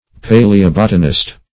Paleobotanist \Pa`le*o*bot"a*nist\, n.
paleobotanist.mp3